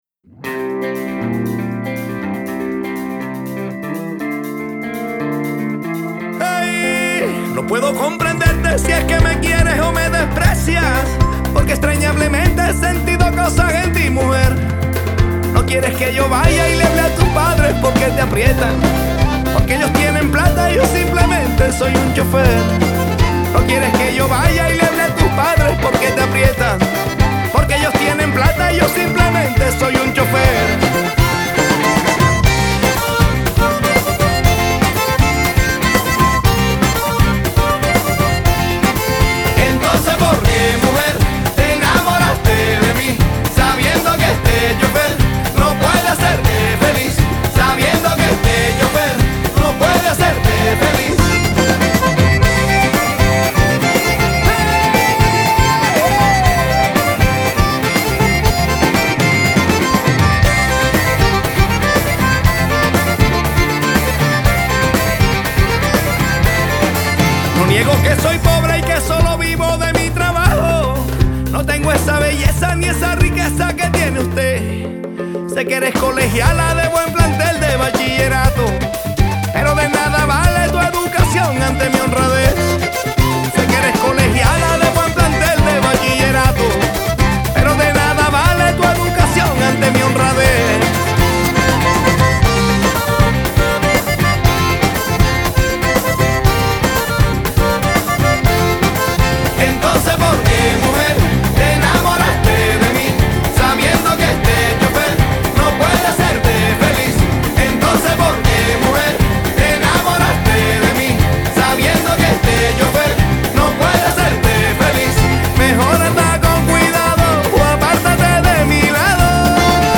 Vallenato